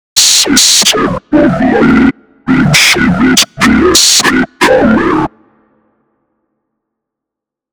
Speech Synth Demo
speech_output.wav